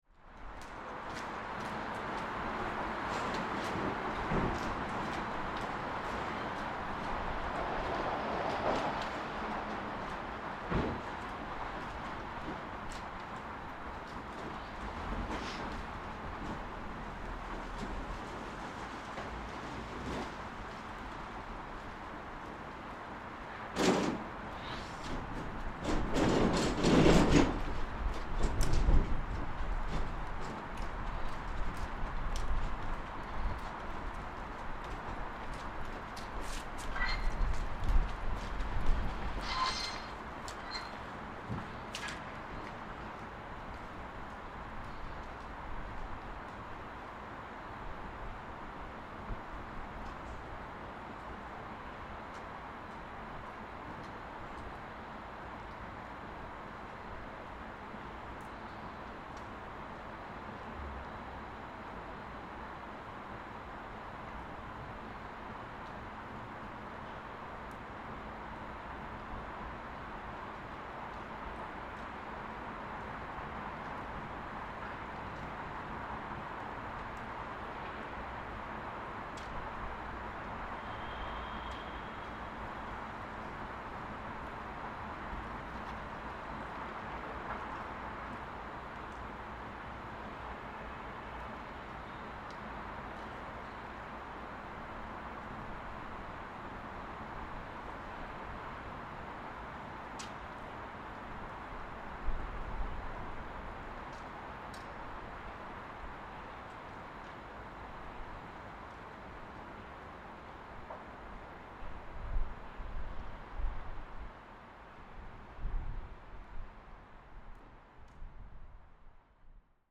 Cathedral Quarter, Belfast during lockdown, 21.10.20
Recording in front of two bars that are now closed (Dirty Onion and Thirsty Goat), the local area is much quieter, windy, and isolated. This is five days after the new Lockdown 2 in Belfast started.